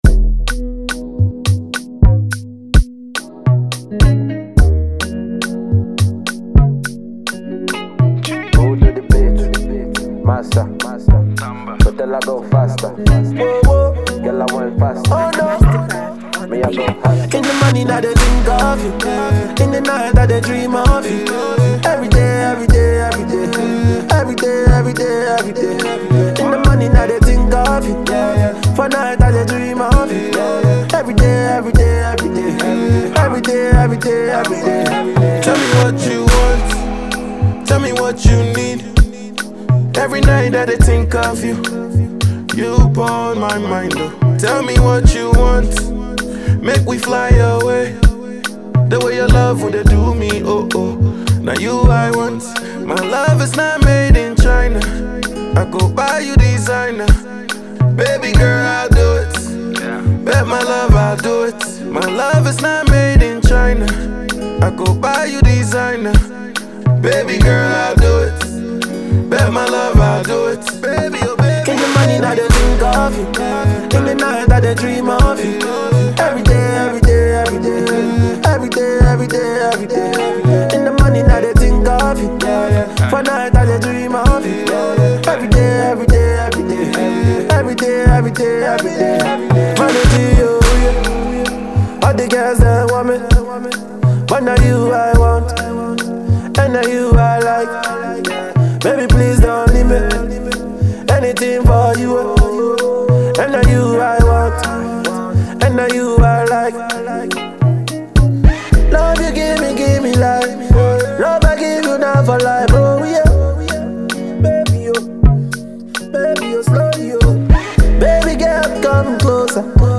Afro-dance-hall